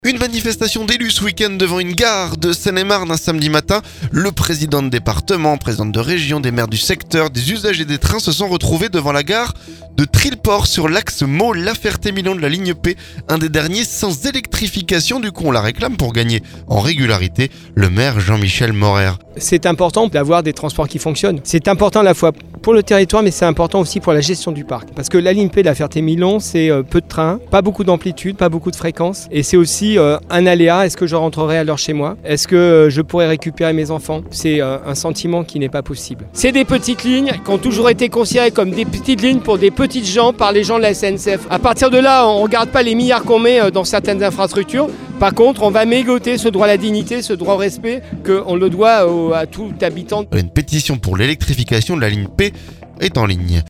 Une manifestation d'élus ce week-end devant une gare de Seine-et-Marne. Samedi matin, le président de Département, la présidente de Région, des maires du secteur, des usagers des trains se sont retrouvés devant la gare de Trilport, sur l'axe Meaux- La Ferté Milon de la ligne P. Ils réclament l'électrification du tronçon, pour gagner en régularité. Le maire Jean-Michel Morer.